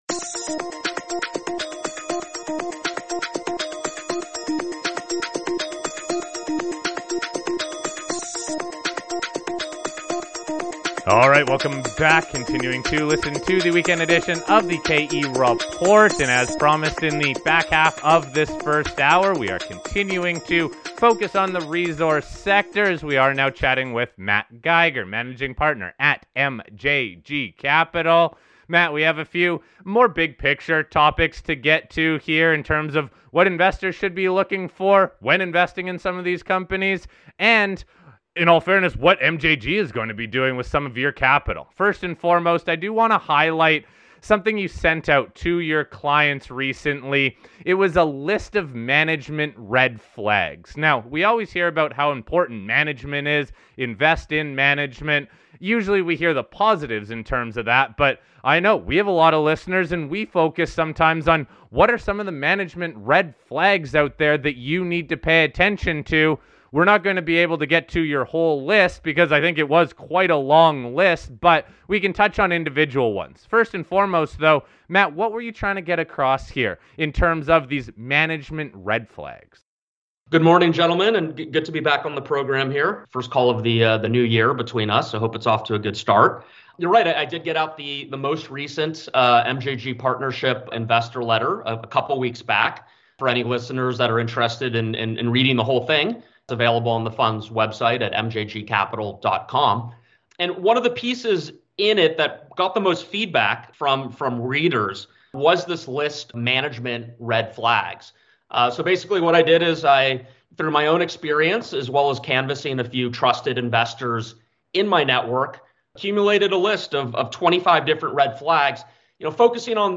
This weekend’s show is focused on resource stocks and how to build a better portfolio. We feature a well known analyst and fund manger to help us better analyze individual companies, avoid management red flags and isolate the commodities they think have the best potential this year.